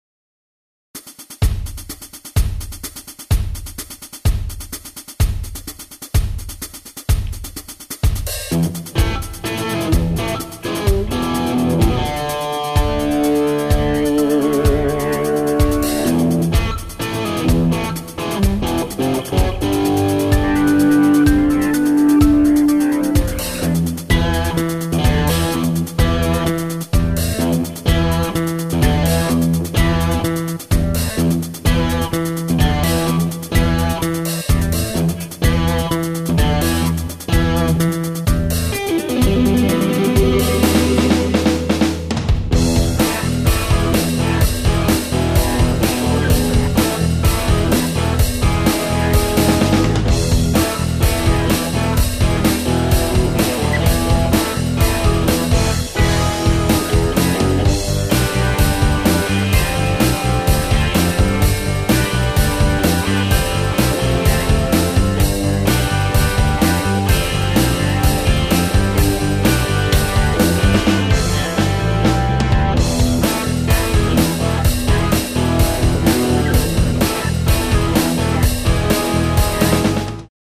Работал над "съёмом" звука двух групп.